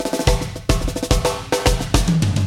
Two Princes drum fill